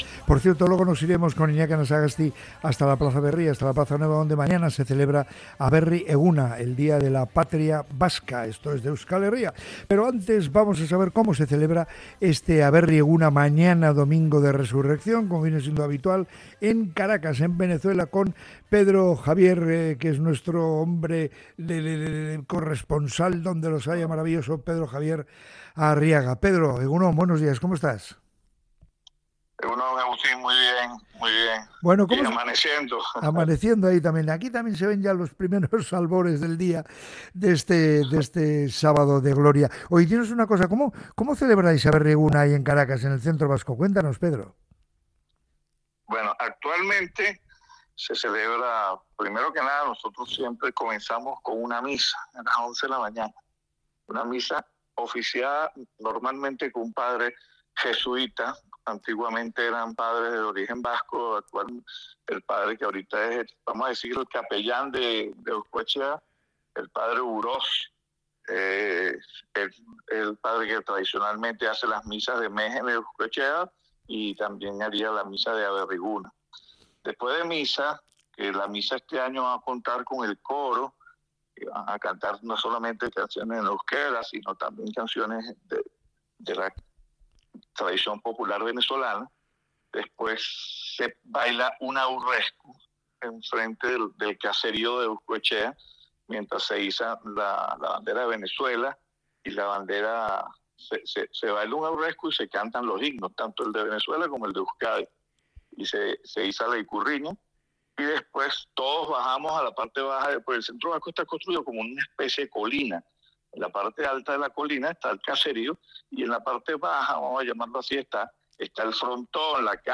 En Moliendo Café, hemos hecho las maletas radiofónicas para cruzar el charco y conectar directamente con Caracas, Venezuela.